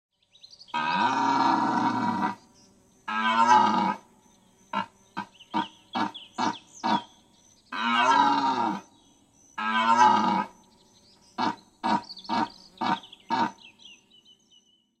Kategorien Tierstimmen